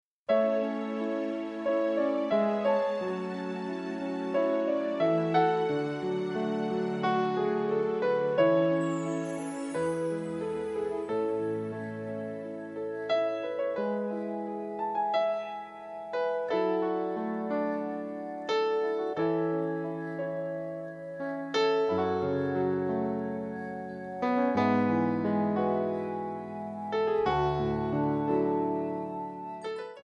MPEG 1 Layer 3 (Stereo)
Backing track Karaoke
Country, Duets, 2000s